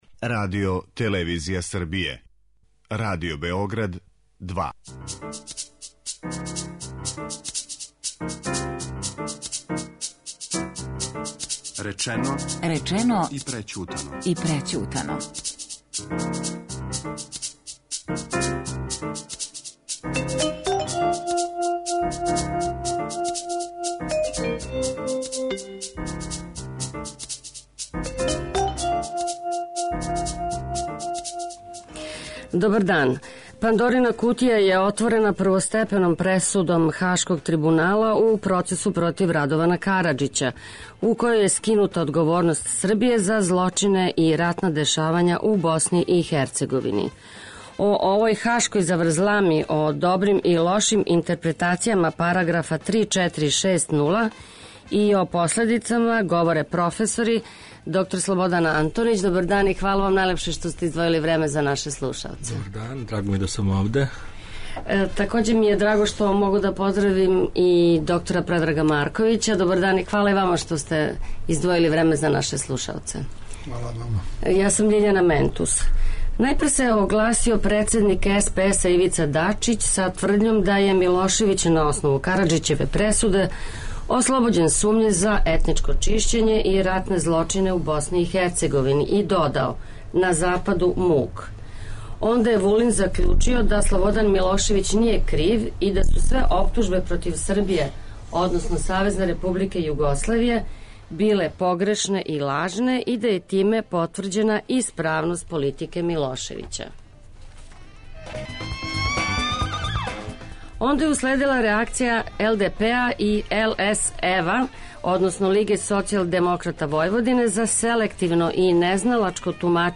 О правом и кривом читању параграфа 3460, и о последицама, говоре учесници емисије: професори